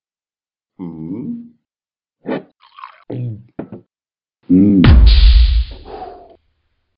Talking Ben Explode 2